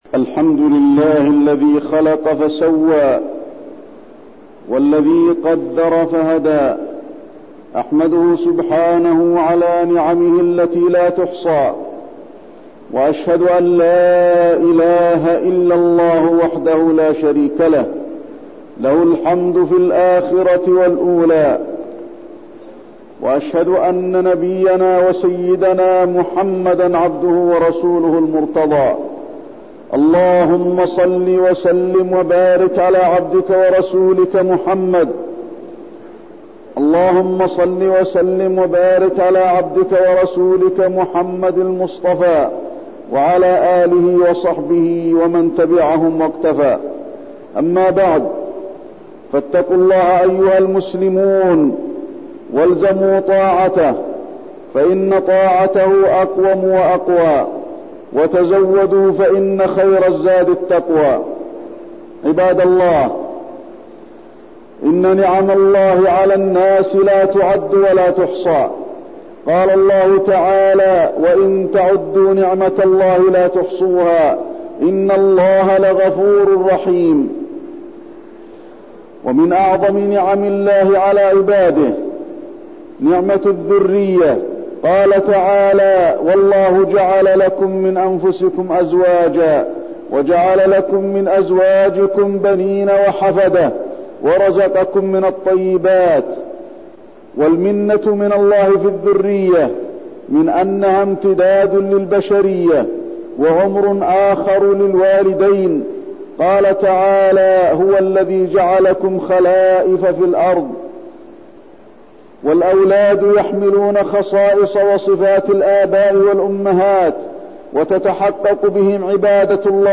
تاريخ النشر ٥ ربيع الثاني ١٤١٠ هـ المكان: المسجد النبوي الشيخ: فضيلة الشيخ د. علي بن عبدالرحمن الحذيفي فضيلة الشيخ د. علي بن عبدالرحمن الحذيفي وإن تعدوا نعمة الله لا تحصوها